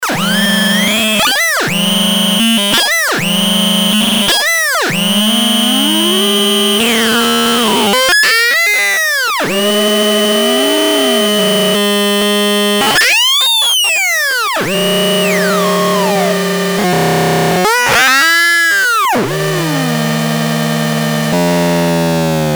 - mono output
edit VOICE this noisebox uses a IC 40106 hex schmitt trigger and it generates 6 identical oscillators with individual pitch frequencies and a non periodic modulator LFO. It works like a source of random noises quite unpredictable but soon boring.